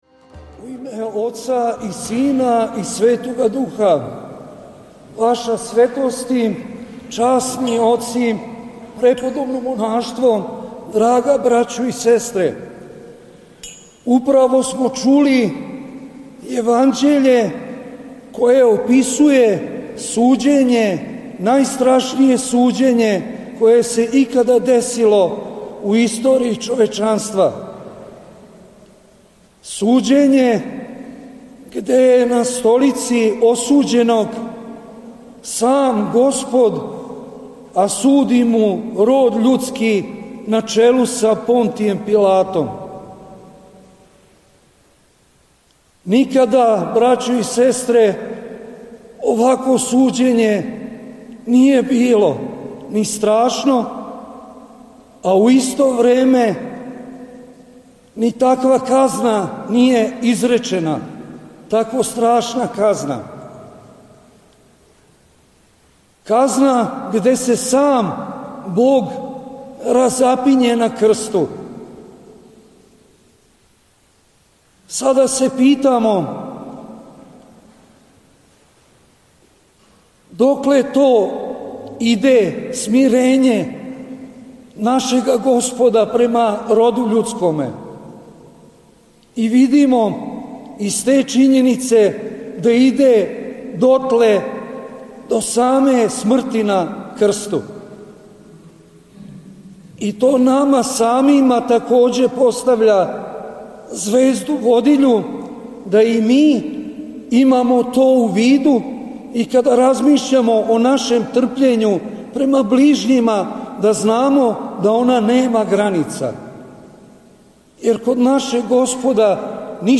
У оквиру серијала „Са амвона“, доносимо звучни запис беседе коју је Његово Преосвештенство Епископ јенопољски г. Никон, викар патријарха српског, изговорио на празник Воздвижења Часног и Животворног Крста, 14/27. септембра 2025. године. Епископ Никон је беседио на светој Литургији у храму Световаведенске обитељи у Београду.